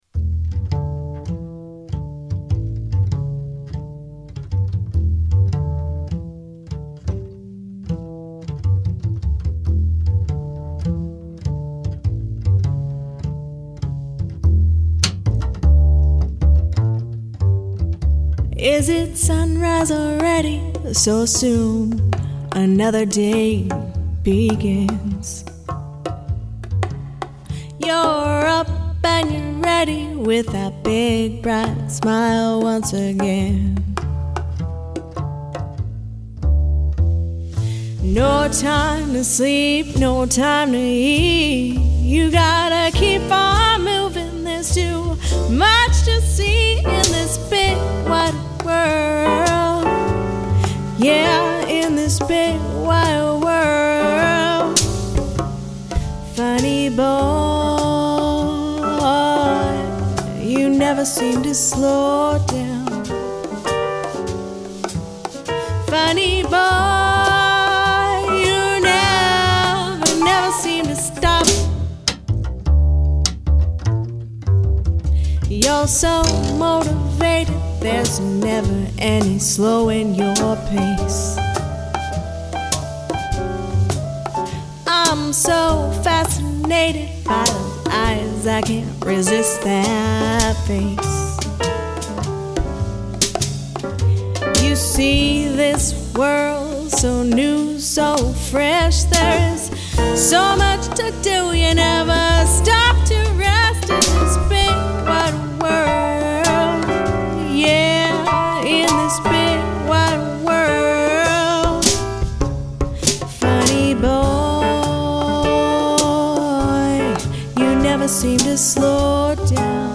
Fresh arrangements of jazz and pop standards and originals